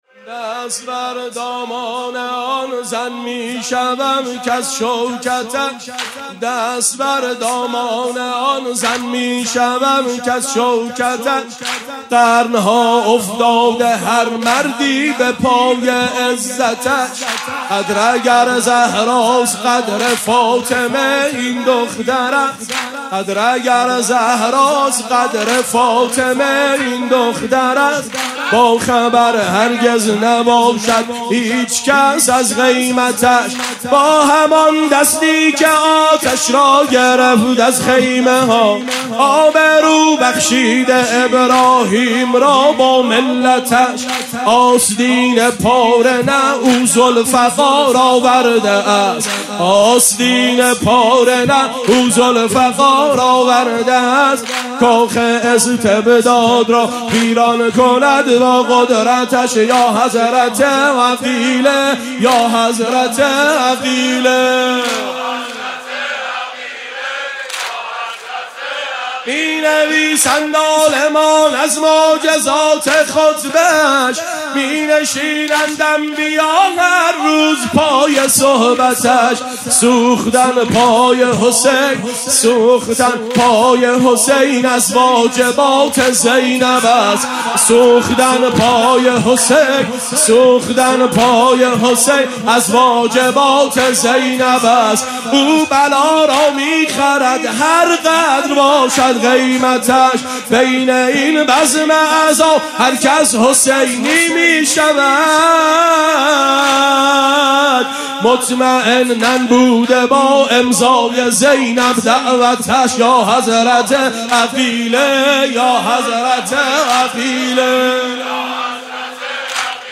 جفت
شب چهارم محرم